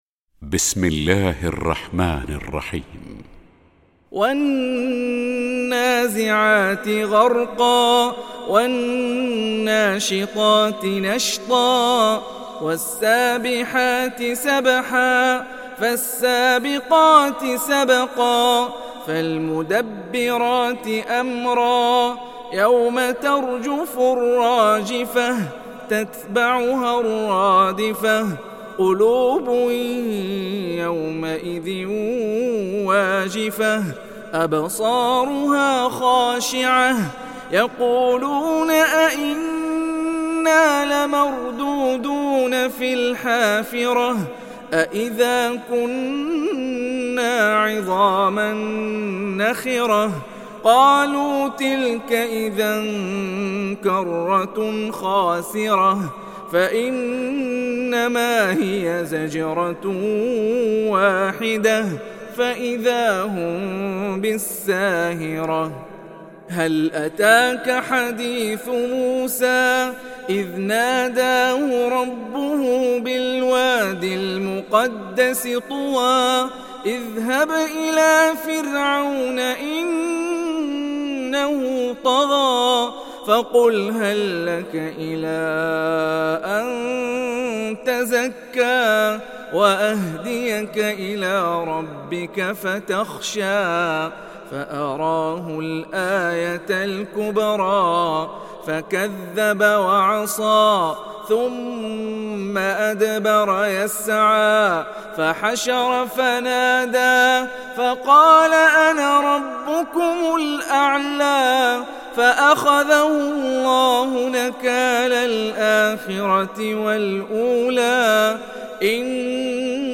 تحميل سورة النازعات mp3 بصوت هاني الرفاعي برواية حفص عن عاصم, تحميل استماع القرآن الكريم على الجوال mp3 كاملا بروابط مباشرة وسريعة